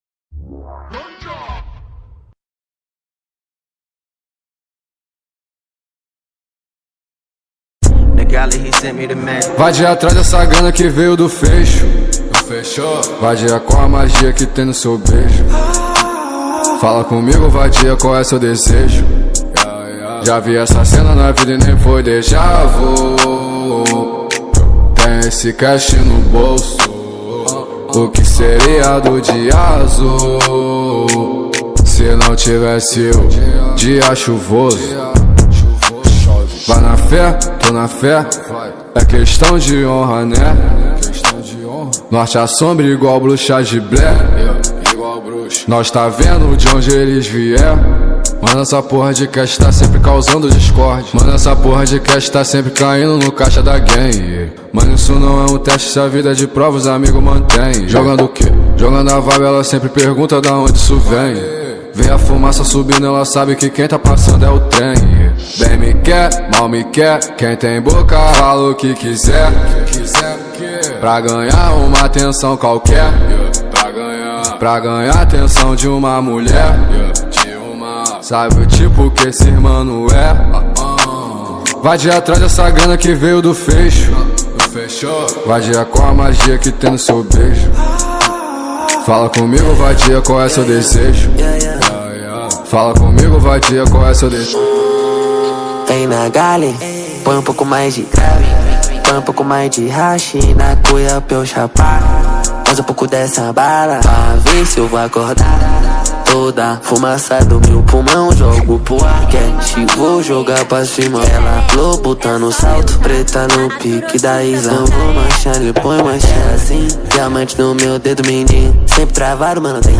2024-03-03 10:47:59 Gênero: Trap Views